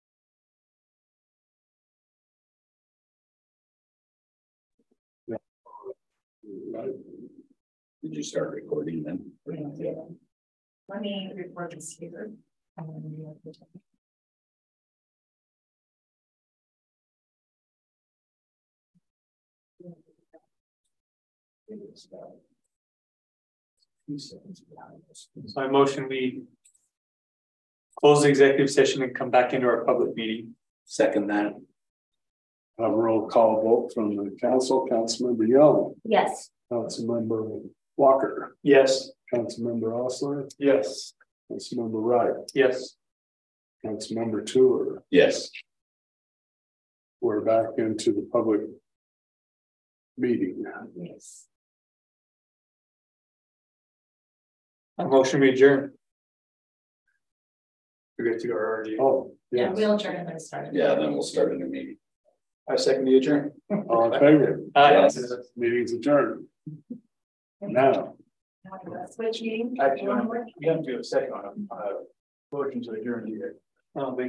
Hearing